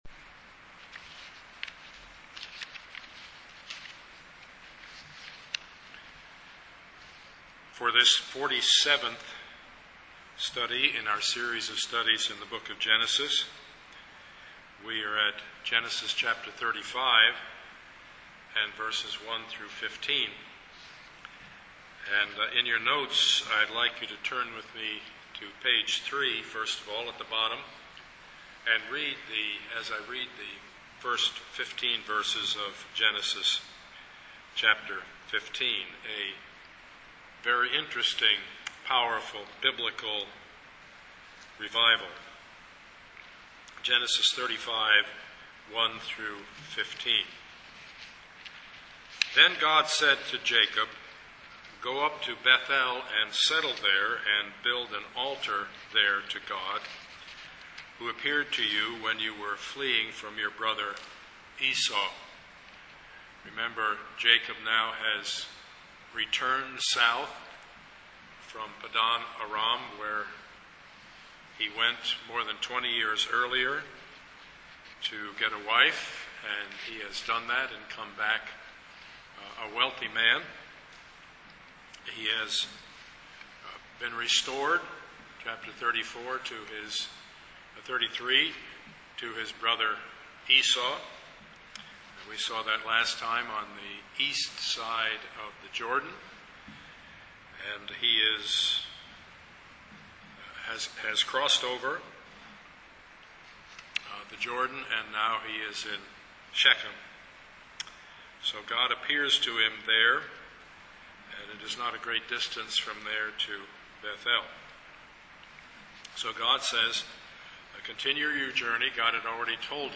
Passage: Genesis 35:1-15 Service Type: Sunday morning
Sermon Series